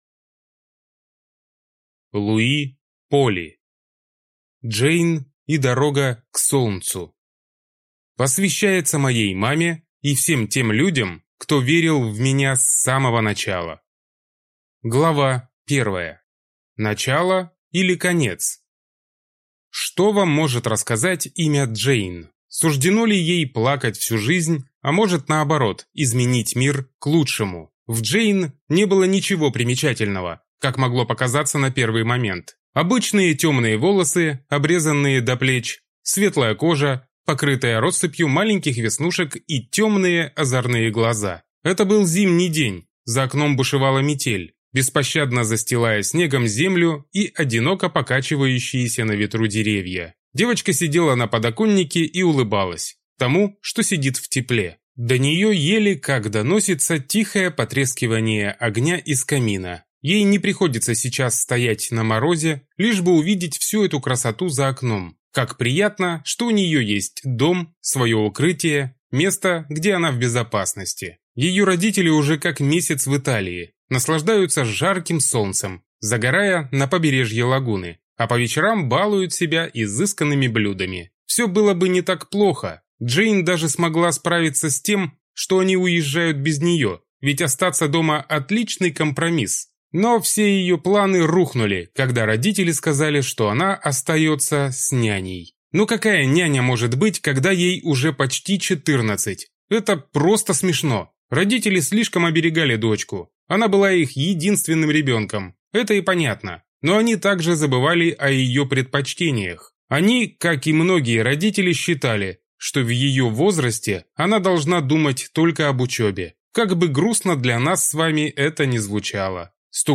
Аудиокнига Джейн и дорога к солнцу | Библиотека аудиокниг